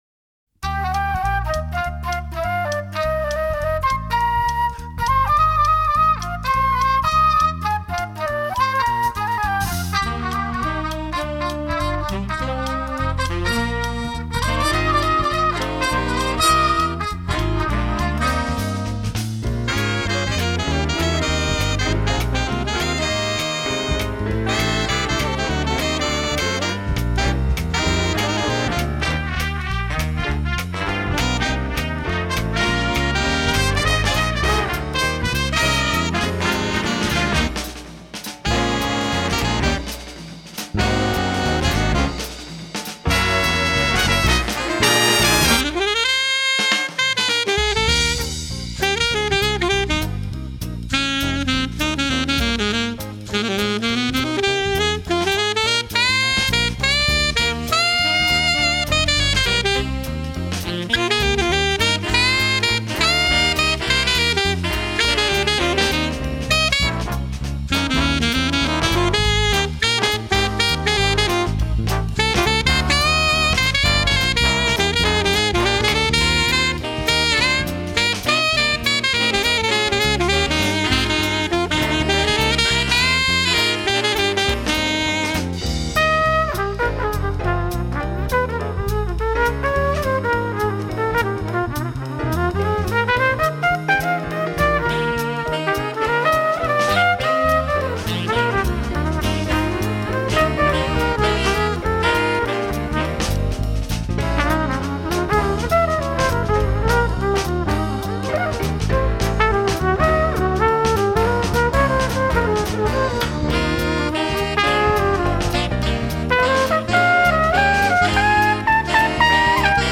MUSIC FOR BIG BAND